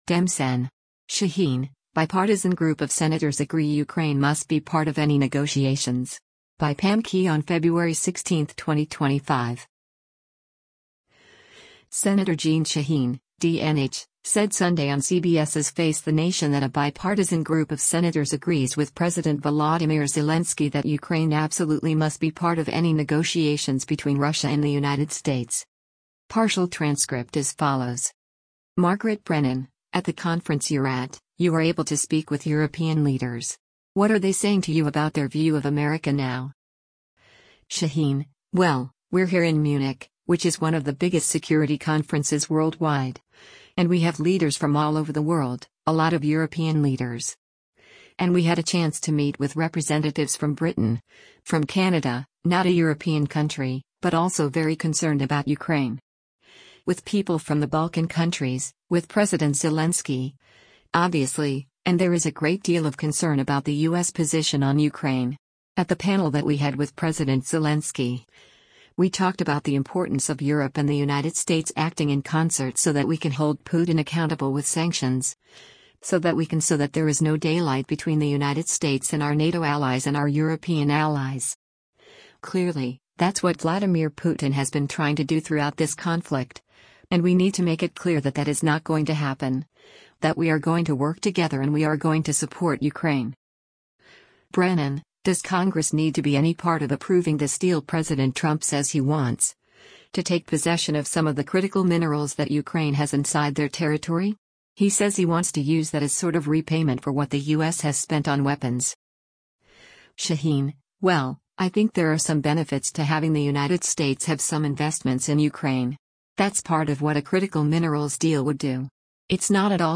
Senator Jeanne Shaheen (D-NH) said Sunday on CBS’s “Face the Nation” that a bipartisan group of senators agrees with President Volodymyr Zelensky that “Ukraine absolutely must be part of any negotiations between Russia and the United States.”